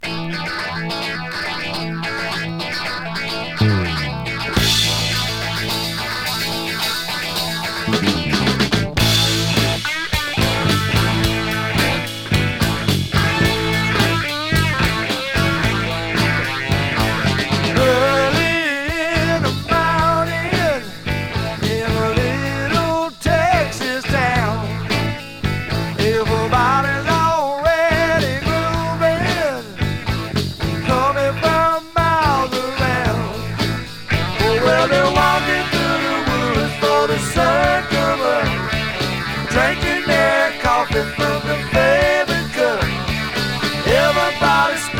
Rock, Country Rock, Blues Rock　USA　12inchレコード　33rpm　Stereo